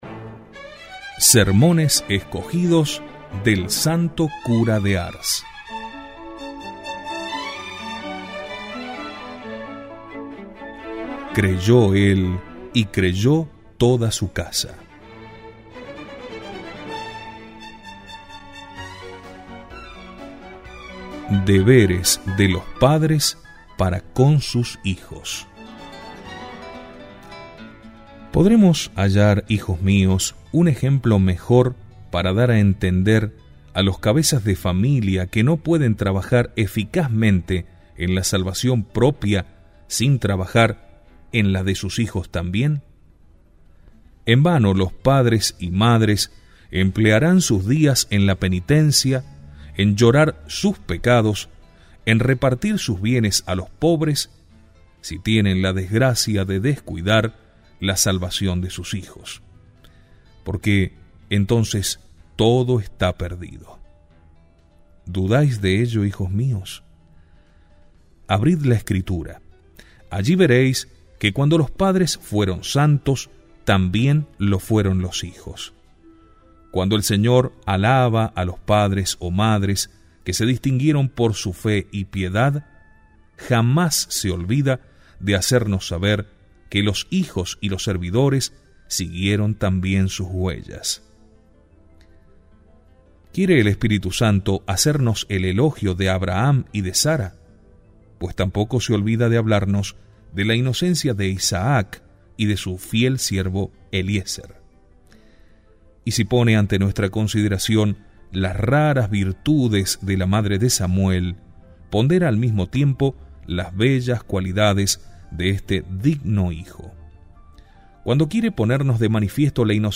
Audio–libros
Sermon-del-Santo-Cura-de-Ars-Deberes-de-los-padres-para-con-los-hijos.mp3